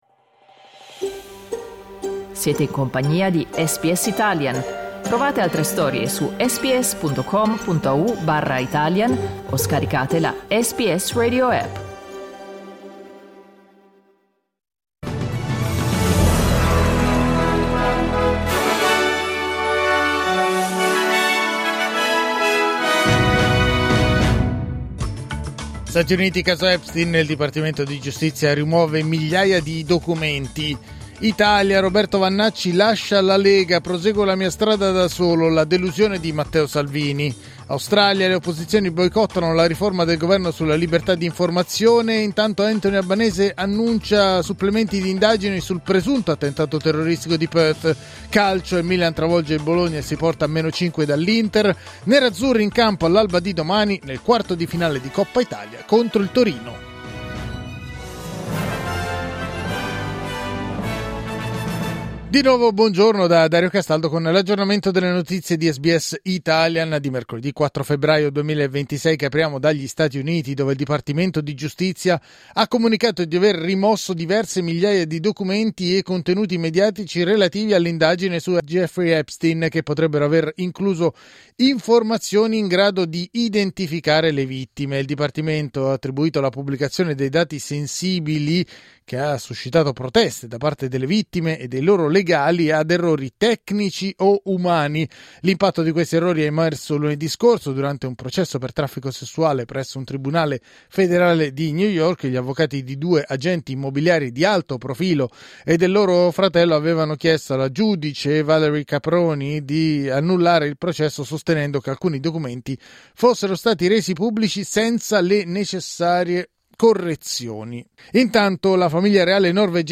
News flash mercoledì 4 febbraio 2026